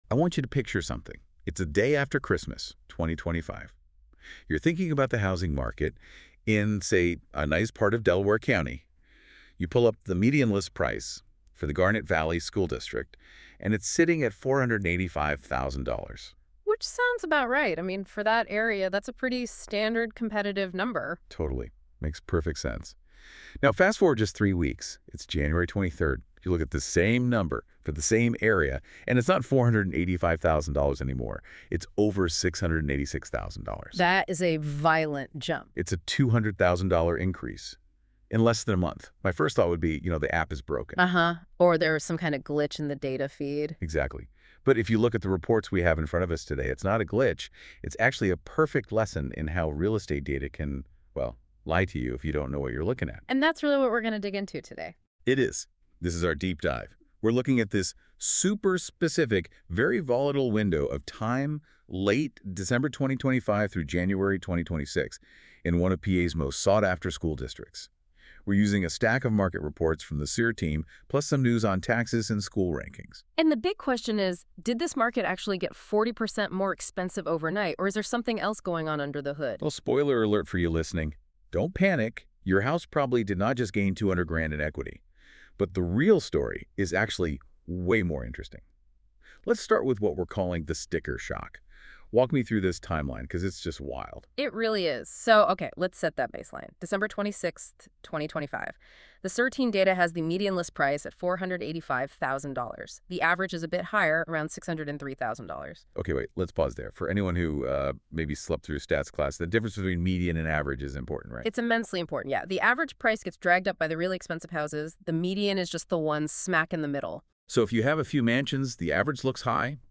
Two market analysts discuss the Garnet Valley School District real estate market across all four weeks of January 2026, covering inventory movement, price shifts, and buyer/seller strategies.